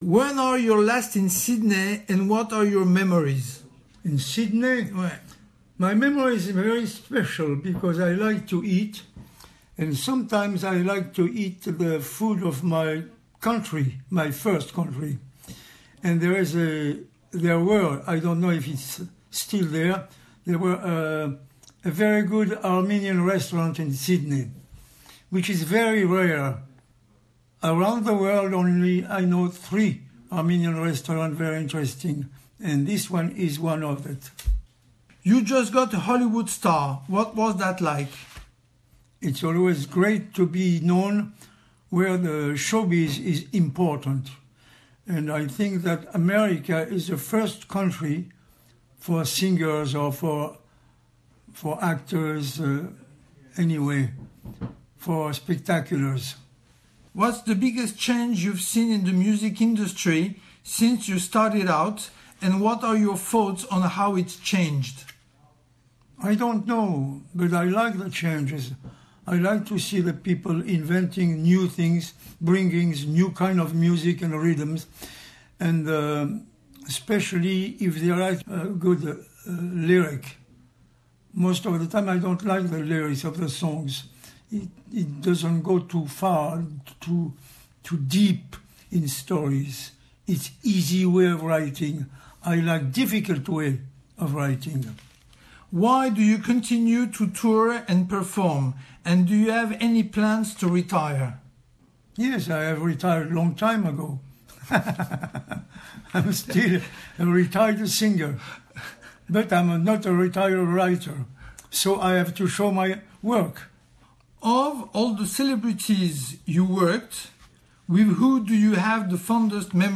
Interview with Charles Aznavour before his arrival to Australia